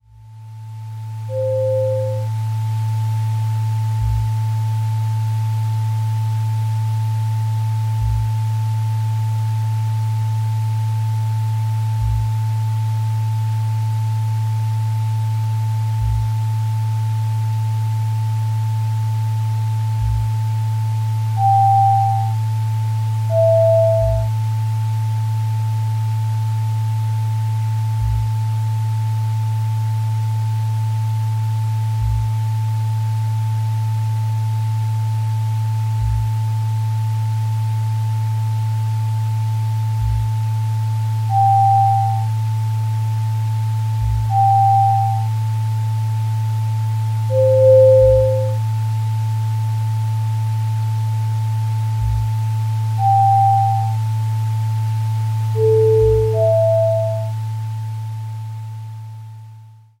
Soft tones and subtle pulses evoke mystery, stillness, and the spark of new inspiration.